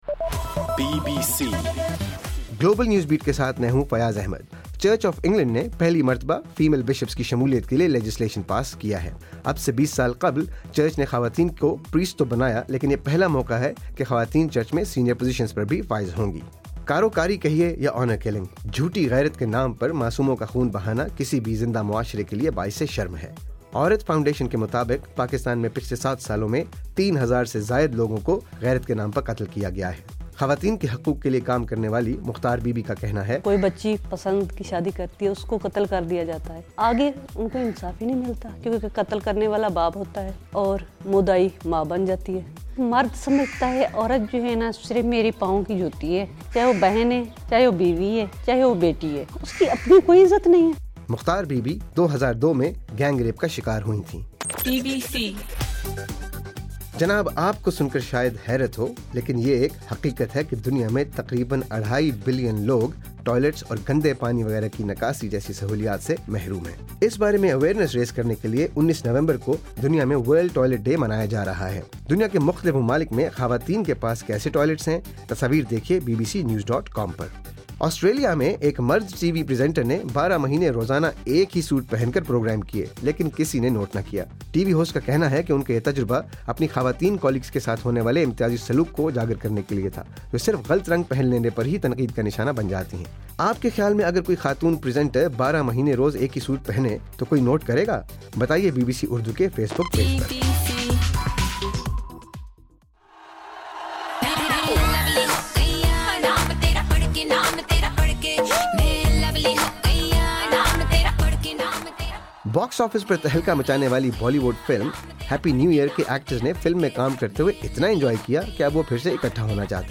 نومبر 17: رات 10 بجے کا گلوبل نیوز بیٹ بُلیٹن